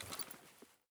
EFT Aim Rattle / gamedata / sounds / weapons / rattle / lower / lower_5.ogg